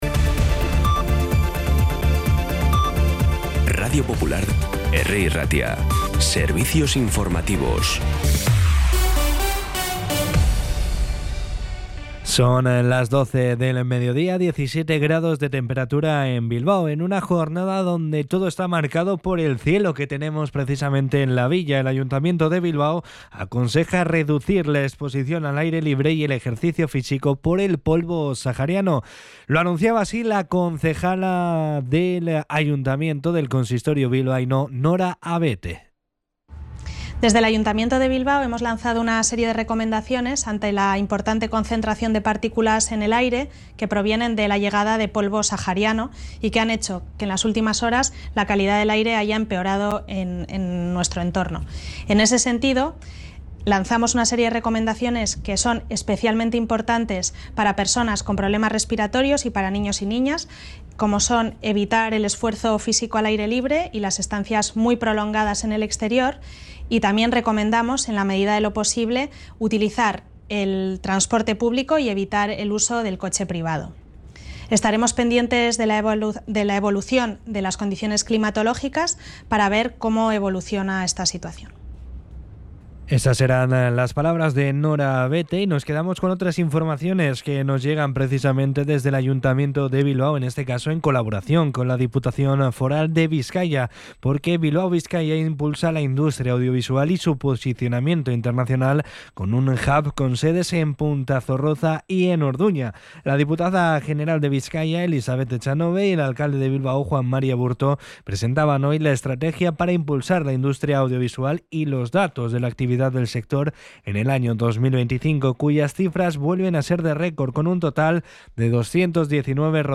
La redacción de informativos de Radio Popular – Herri Irratia trabaja durante todo el día para ofrecerte el resumen informativo más compacto.
Los titulares actualizados con las voces del día. Bilbao, Bizkaia, comarcas, política, sociedad, cultura, sucesos, información de servicio público.